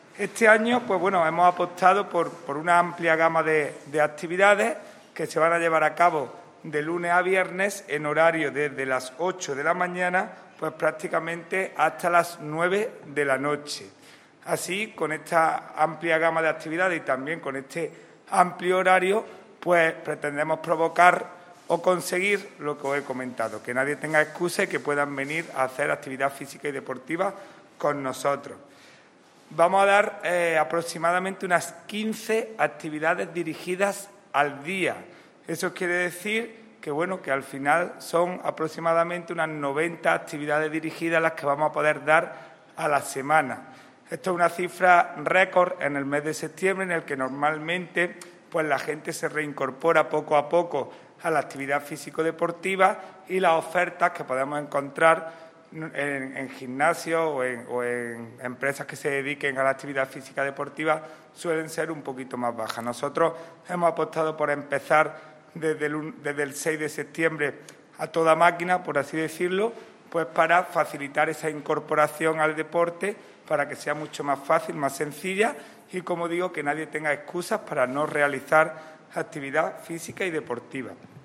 El teniente de alcalde delegado de Deportes del Ayuntamiento de Antequera, Juan Rosas, informa del inicio de las actividades colectivas dirigidas de la nueva temporada 2021/2022 tanto en lo que respecta a las salas multidisciplinares del Pabellón Polideportivbo Fernando Argüelles como en la Piscina Cubierta Municipal.
Cortes de voz